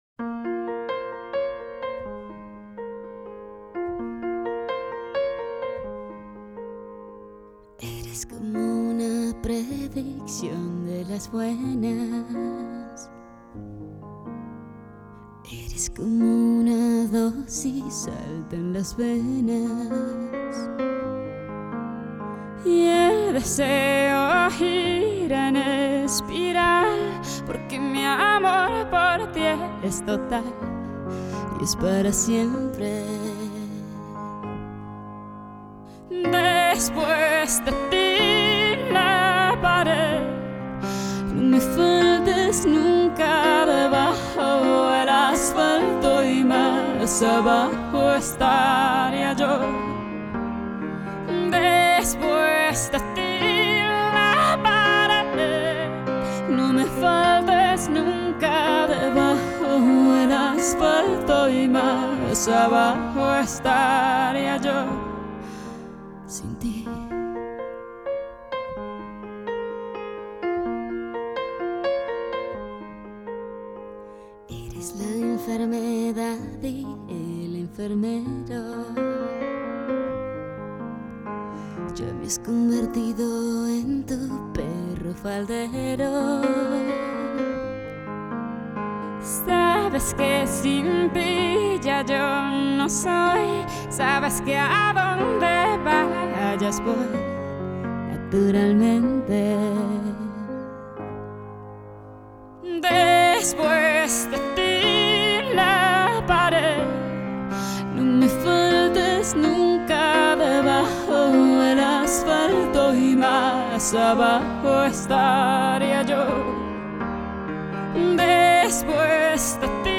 Versión Acústica